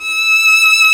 Index of /90_sSampleCDs/Roland L-CD702/VOL-1/STR_Violin 2&3vb/STR_Vln3 % marc
STR VLN3 E 5.wav